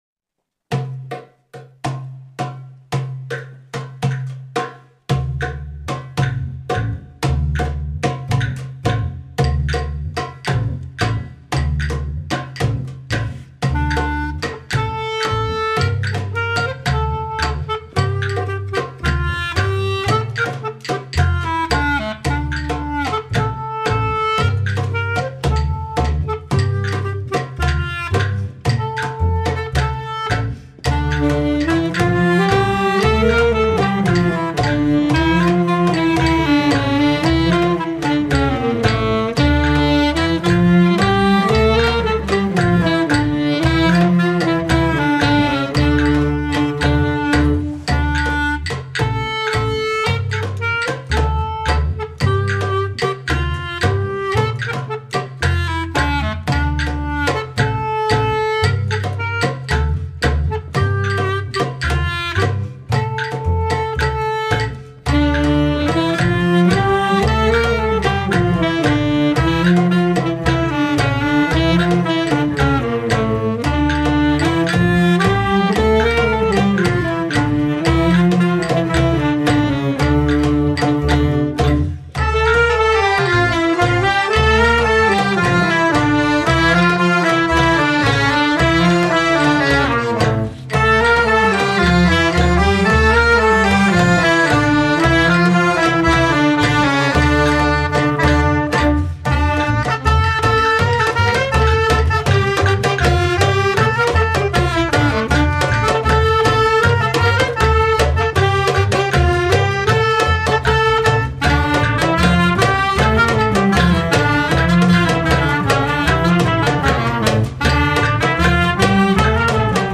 (Traditional Music)